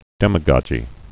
(dĕmə-gŏjē, -gôjē, -gŏgē, -gōjē)